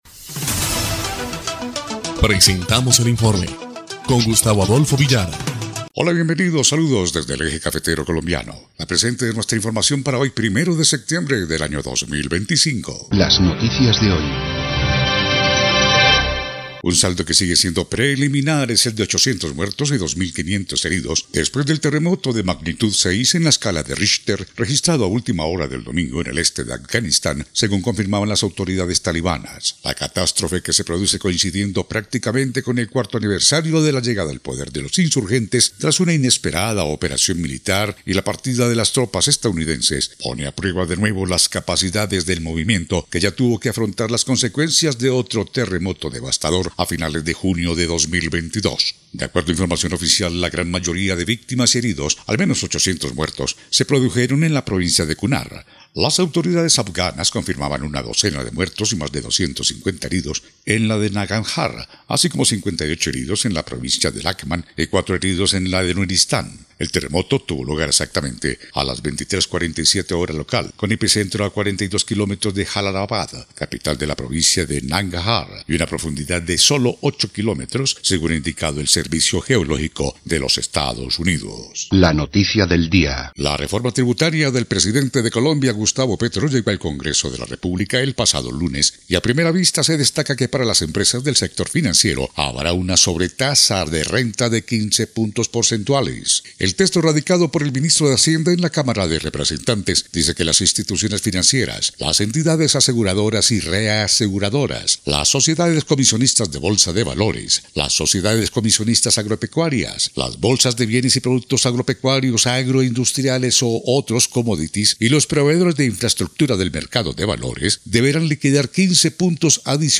EL INFORME 3° Clip de Noticias del 2 de septiembre de 2025